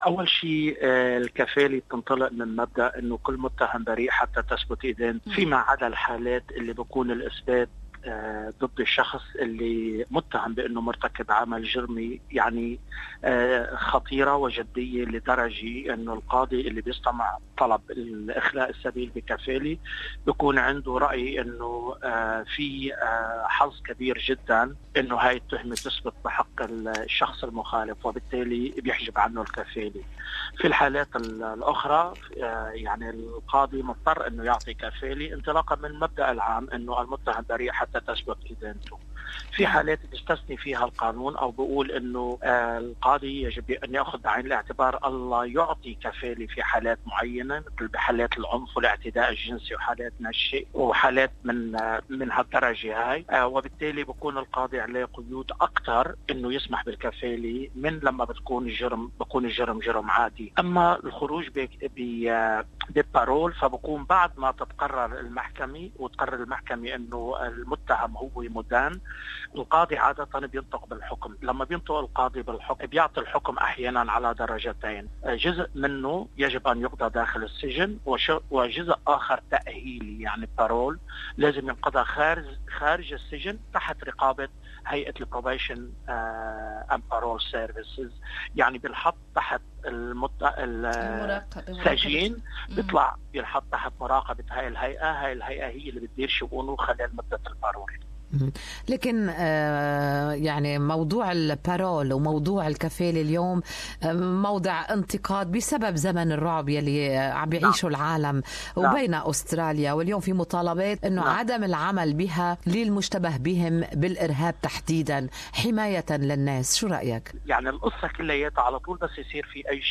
During his interview with Good Morning Australia